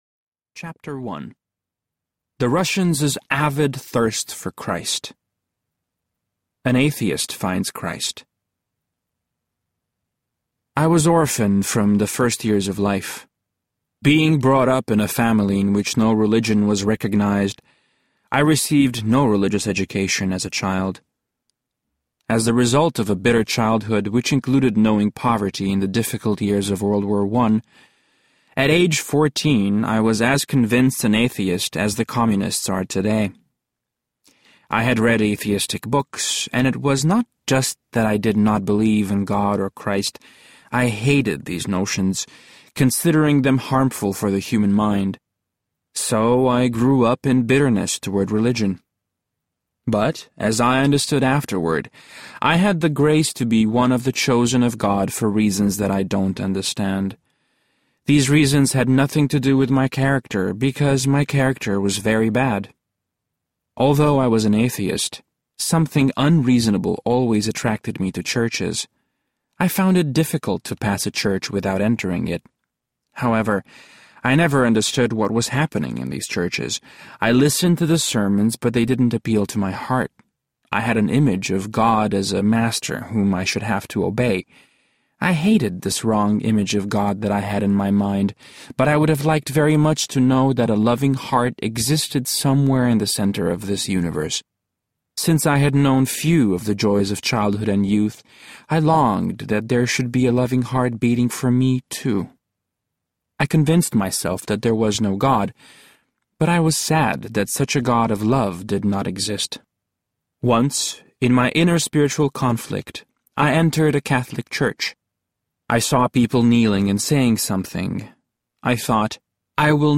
Tortured for Christ Audiobook